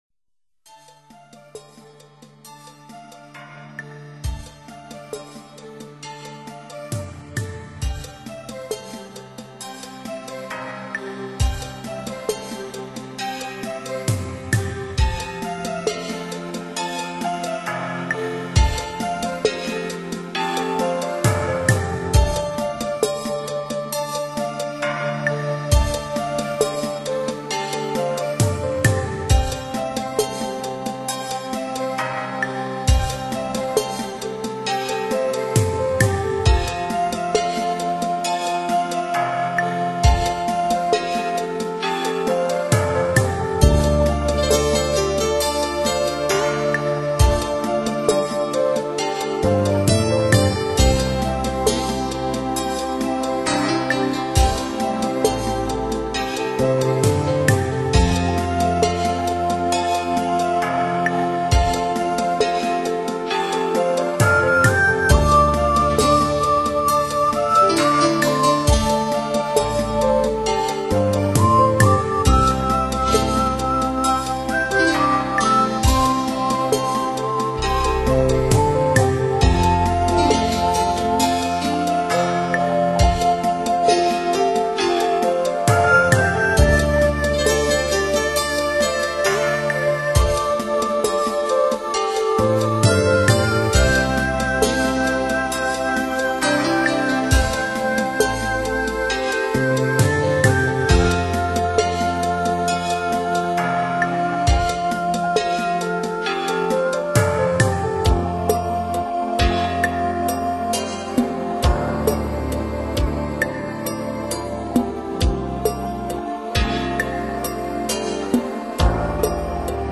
作曲及演奏是现代化的midi，但骨子里的精神却是最传统的中国文化，这正是cd中音乐最精彩之处。